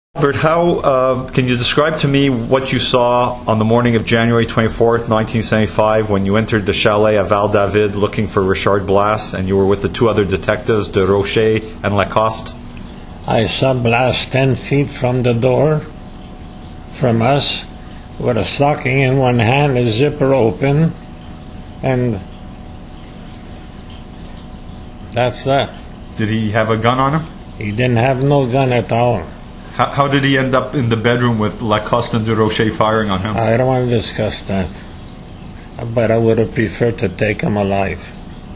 recounting in his own words
2012 interview what happened when police raided a chalet north of Montreal at 1:30 a.m. on January 24